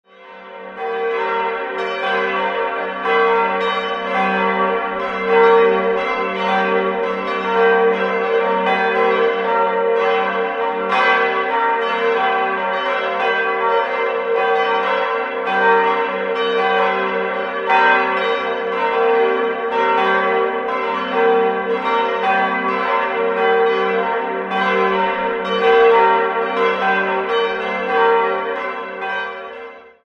Die Pfarrkirche ist im modernen Stil erbaut. 4-stimmiges ausgefülltes G-Moll-Geläute: g'-b'-c''-d'' Friedrich Wilhelm Schilling goss diese vier Glocken im Jahr 1959. Sie wiegen 920, 501, 348 und 239 kg.